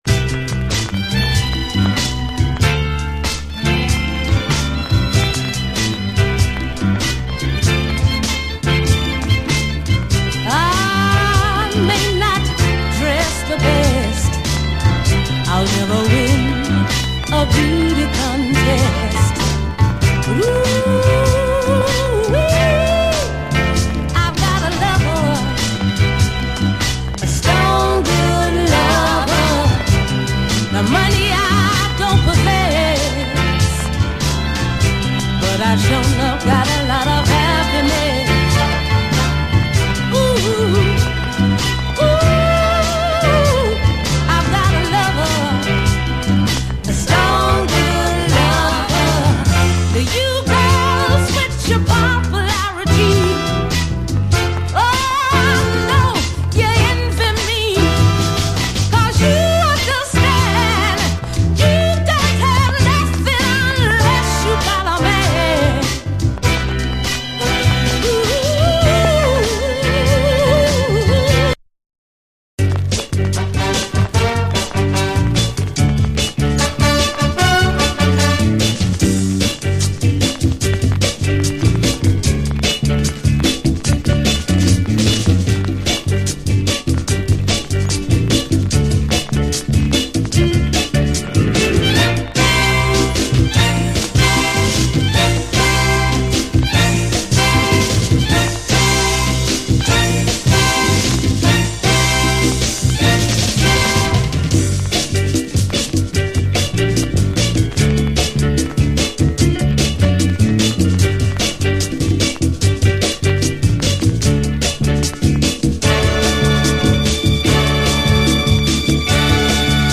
イイ曲だらけのオランダ産ファンキー・ミドルスクール〜ヒップ・ハウス！